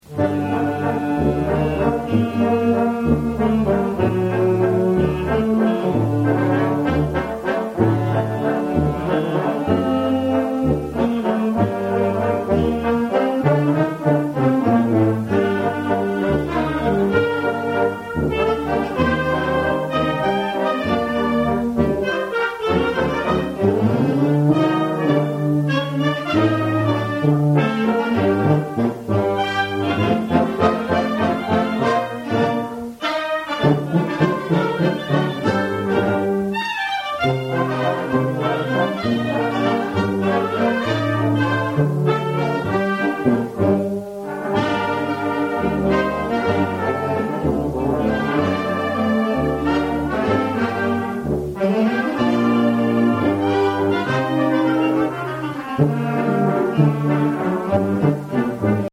Ragtimes, valses & bostons